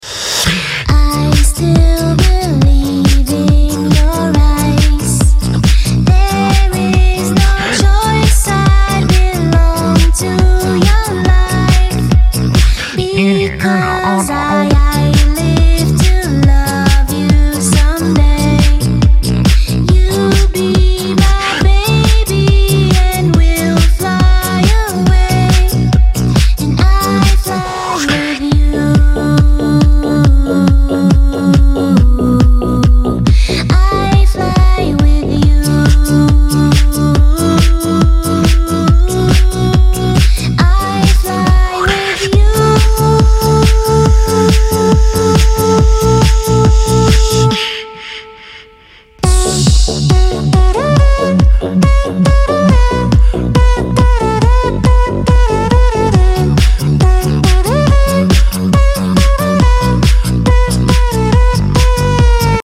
beatbox cover